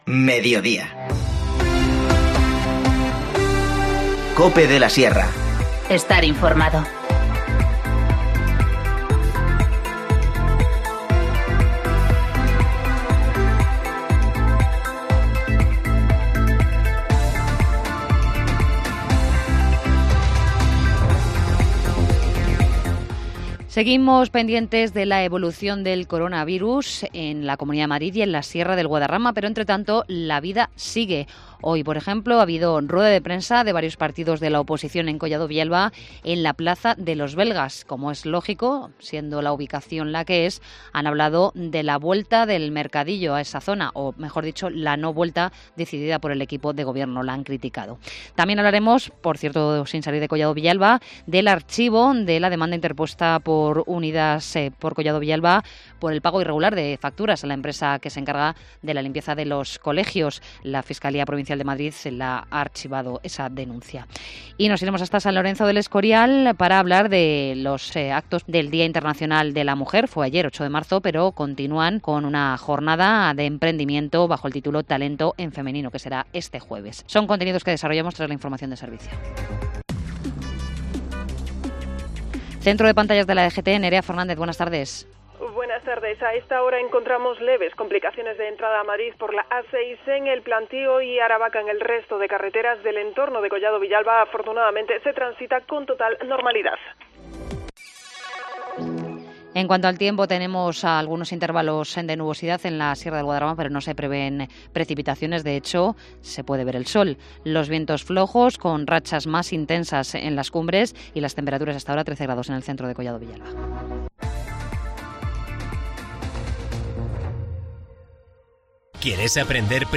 Informativo Mediodía 9 marzo 14:20h